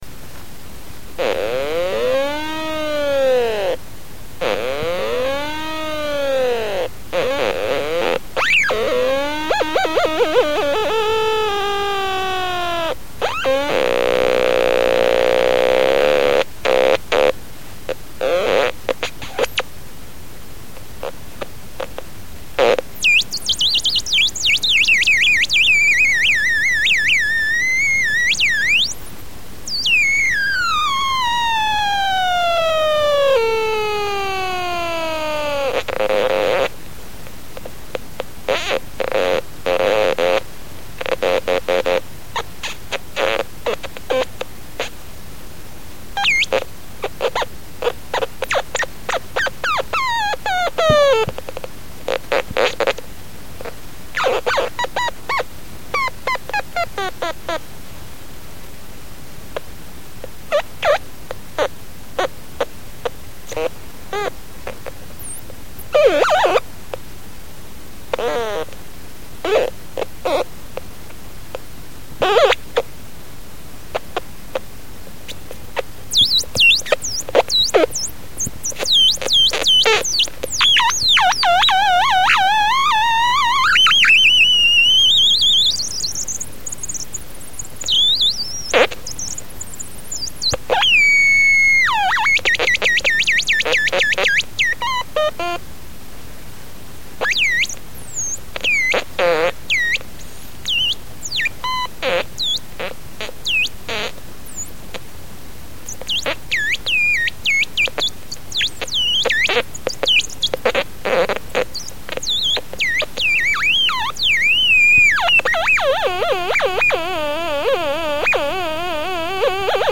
Descarga de Sonidos mp3 Gratis: alarma 11.
bip_2.mp3